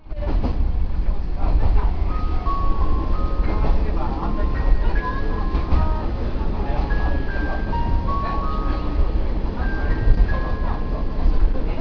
〜車両の音〜
キハ52形 車内チャイム
国鉄気動車に搭載されていた「アルプスの牧場」のオルゴールが搭載されています。国鉄時代は最初から最後まで流れる事が殆ど無いことで有名だったこのチャイムですが、自分が乗車していた際はきれいに流れていました。